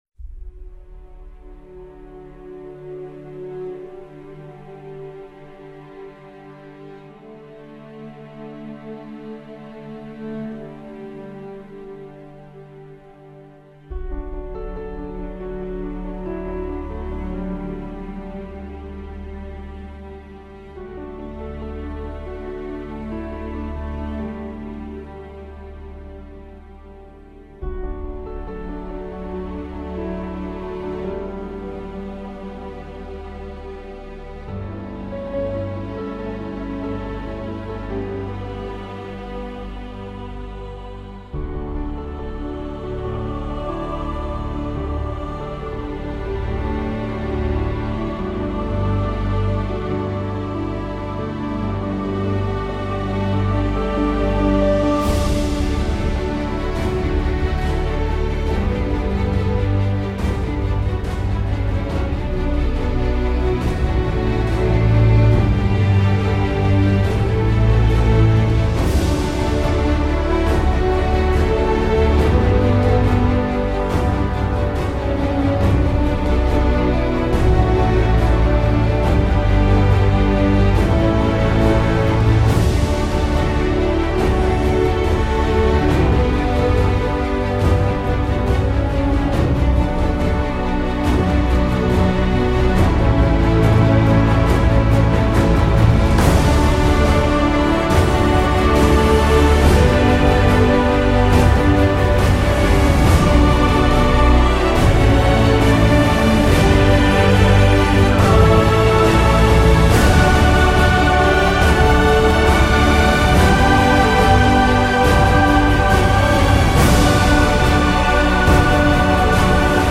Enjoy the 30 min of a great up and down music about an epic ledgendarry warrior that has come to my ancient lands.